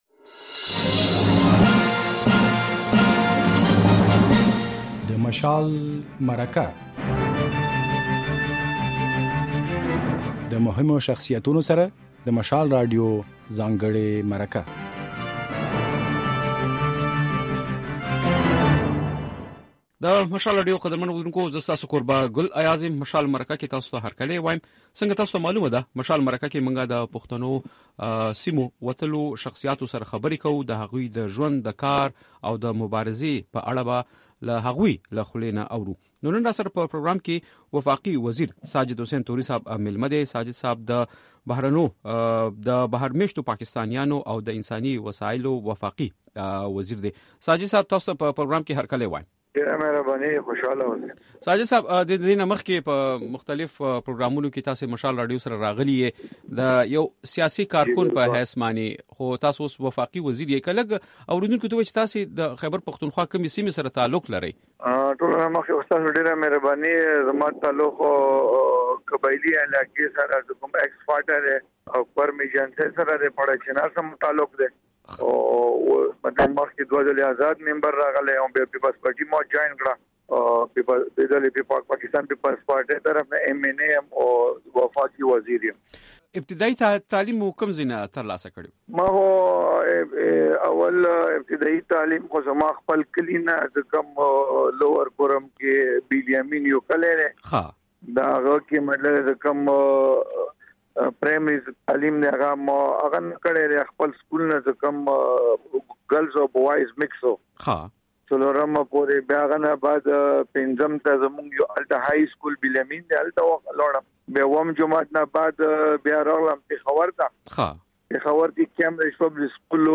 د مشال راډيو په اوونيزه خپرونه "د مشال مرکه" کې دا ځل د بهر ميشتو پاکستانيانو د چارو وفاقي وزير ساجد حسېن توری مېلمه دی.